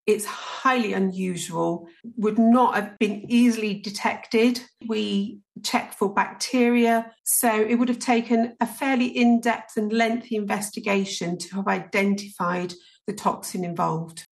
Food scientist